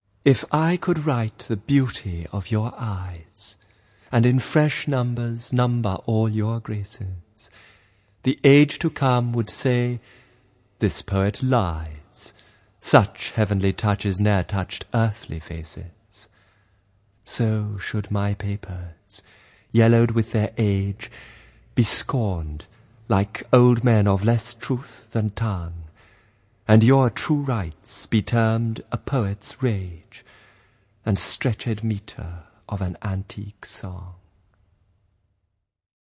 Reading 4: